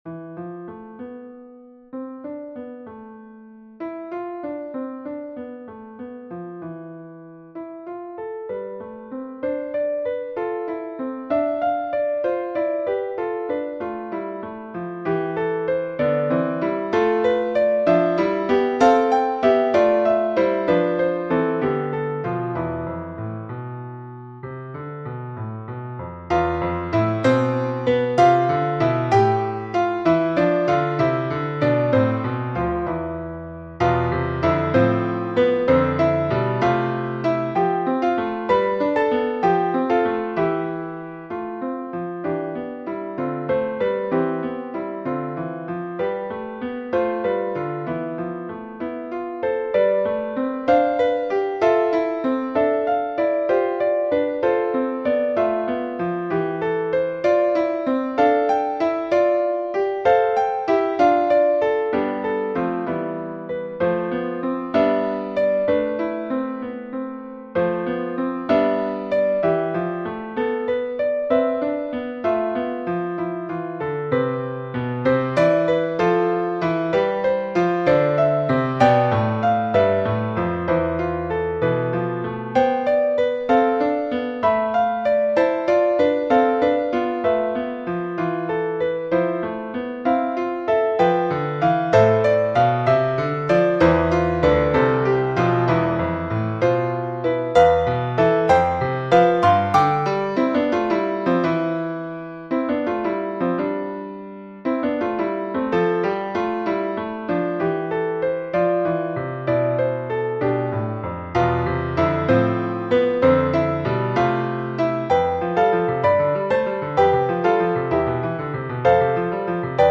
Classical Period, Modern Classical